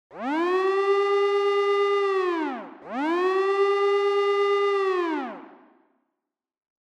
警報
Warning-Siren02.mp3